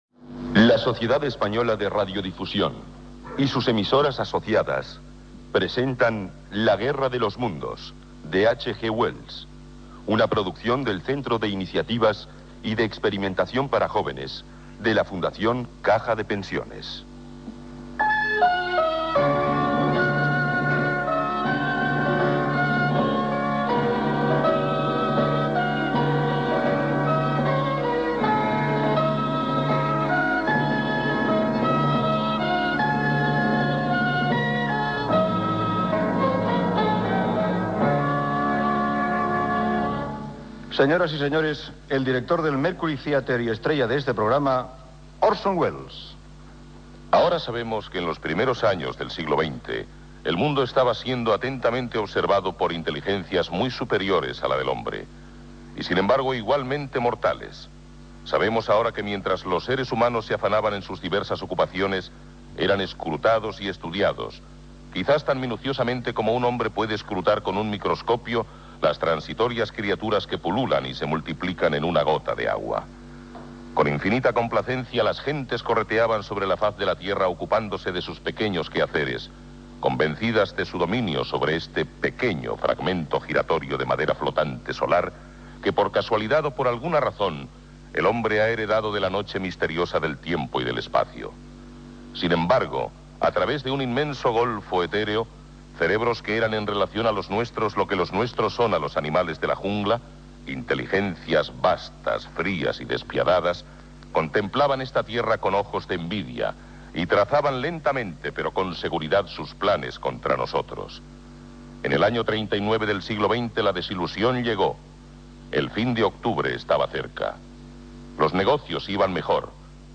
Careta del programa, paraules inicials d'Orson Wells i fragment del principi de la ficció sonora.
Fragment final de l'obra, paraules finals d'Orson Wells i careta de sortida amb el repartiment
Ficció